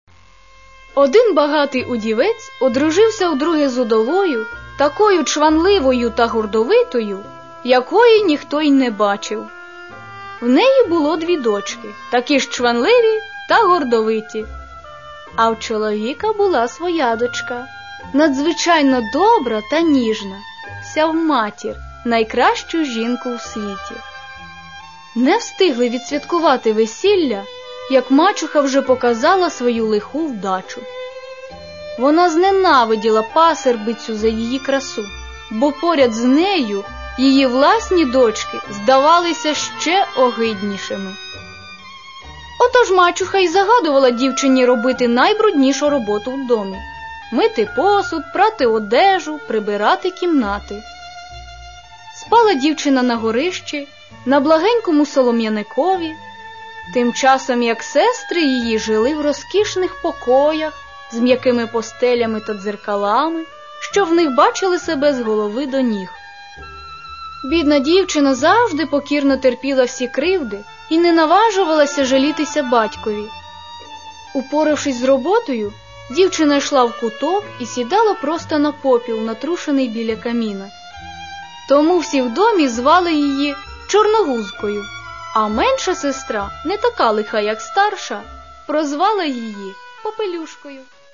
Каталог -> Аудио книги -> Детская литература
Имею ввиду не только разнообразное музыкальное оформление, но и голоса чтецов.
Впрочем, и голоса остальных актеров тоже не уступают ей в выразительности, нежности – впечатление такое, словно читают для собственных детей.